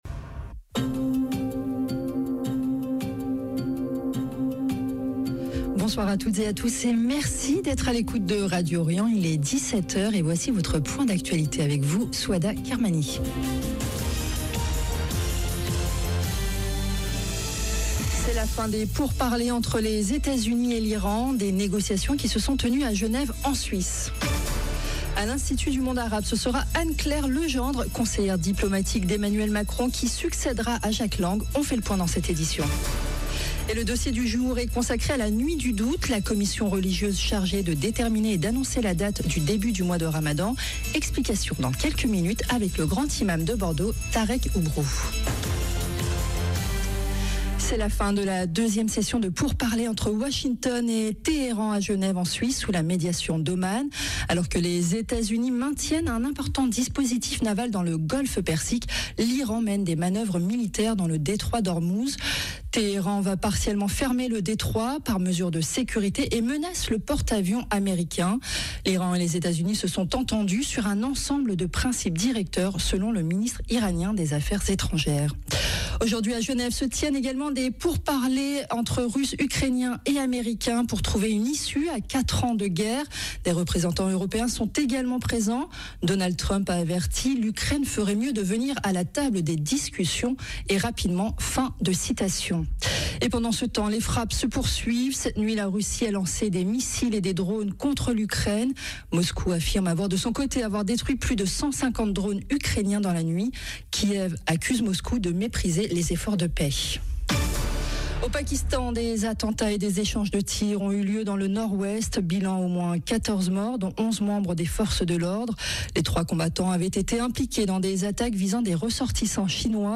Radio Orient Journal de 17H